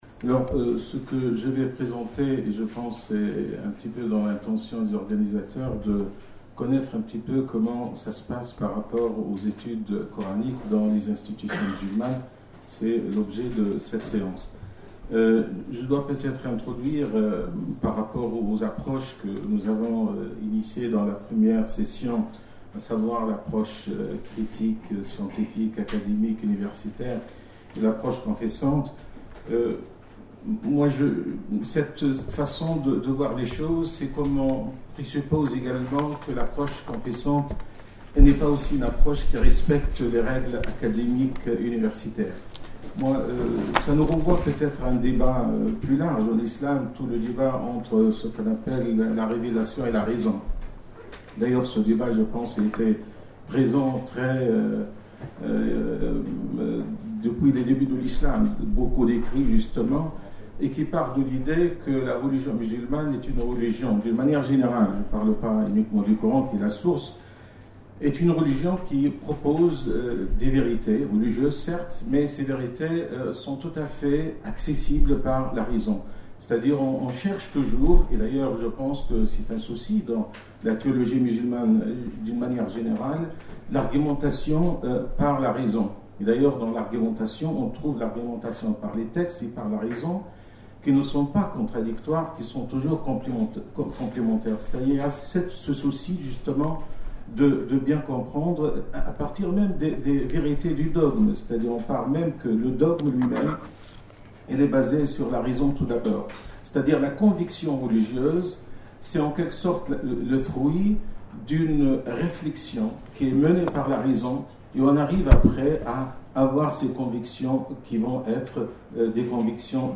Institut confessionnel La pratique des études coraniques à l’IESH de Paris Accédez à l'intégralité de la conférence en podcast audio dans l'onglet téléchargement